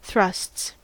Ääntäminen
Ääntäminen US Tuntematon aksentti: IPA : /ˈθɹʌsts/ Haettu sana löytyi näillä lähdekielillä: englanti Thrusts on sanan thrust monikko.